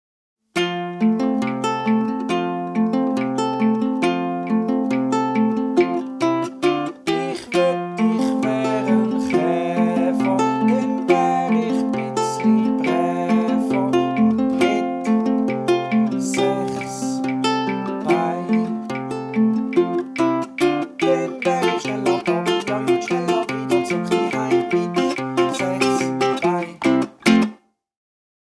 Lied
02-Chaefer-Audio-Karaoke-001.mp3